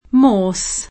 vai all'elenco alfabetico delle voci ingrandisci il carattere 100% rimpicciolisci il carattere stampa invia tramite posta elettronica codividi su Facebook Morse [ingl. m 0 o S ] cogn. — nella locuz. alfabeto M. (o m. ), spesso con pn. italianizz. [ m 0 r S e ]